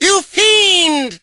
mortis_hurt_02.ogg